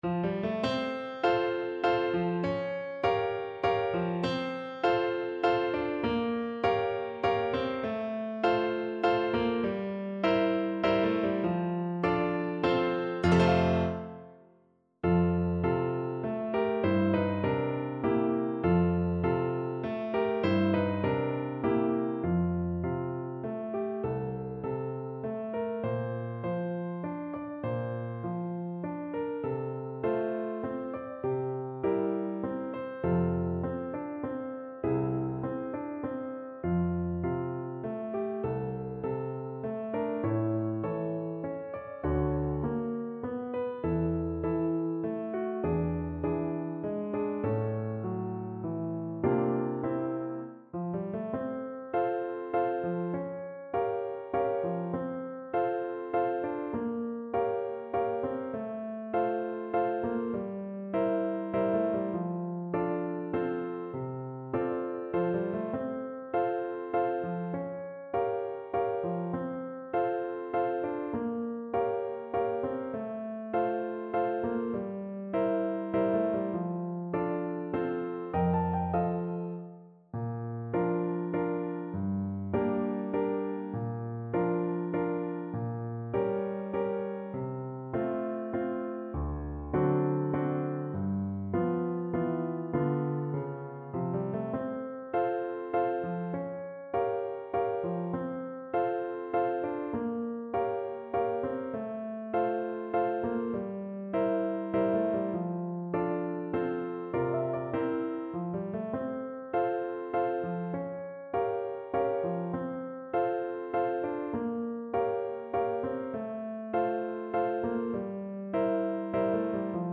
3/4 (View more 3/4 Music)
~ = 100 Valse moderato
Pop (View more Pop French Horn Music)